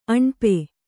♪ aṇpe